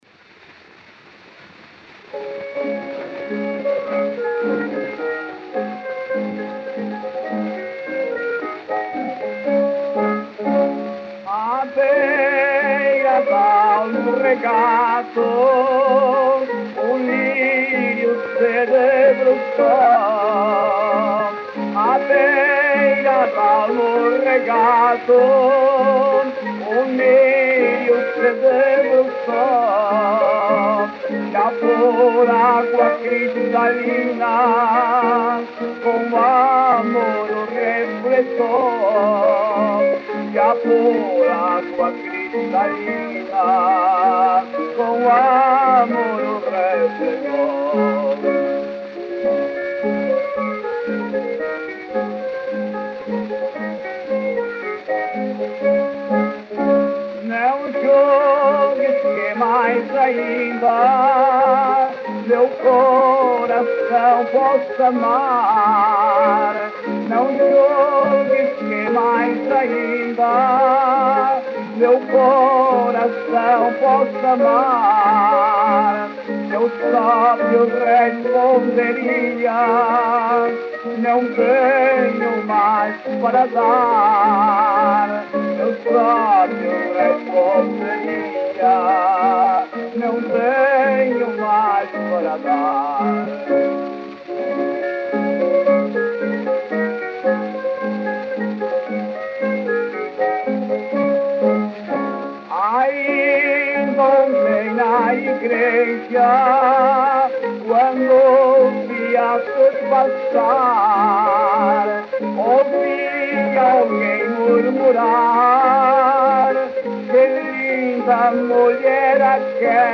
Gênero: Fado corrido.